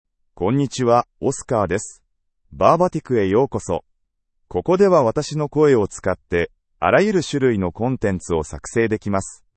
OscarMale Japanese AI voice
Oscar is a male AI voice for Japanese (Japan).
Voice sample
Listen to Oscar's male Japanese voice.
Oscar delivers clear pronunciation with authentic Japan Japanese intonation, making your content sound professionally produced.